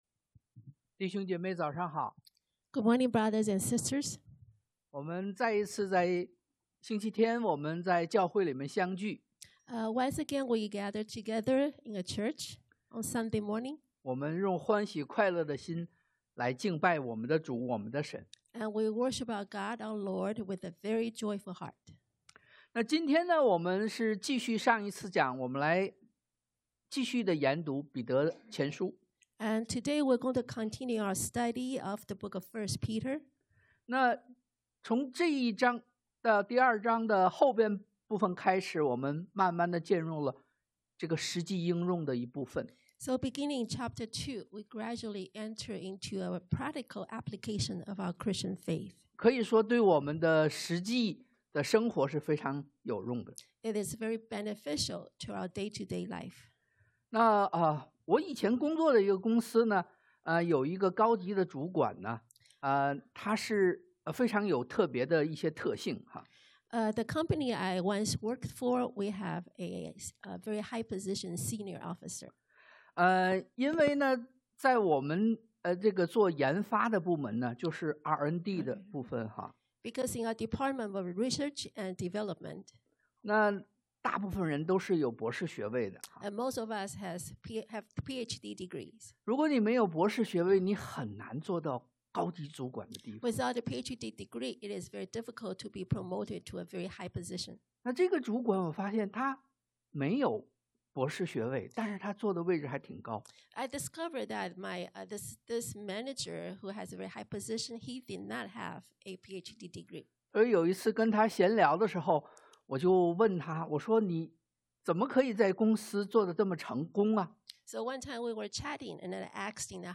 Passage: 彼前 1 Peter 2:11-25 Service Type: Sunday AM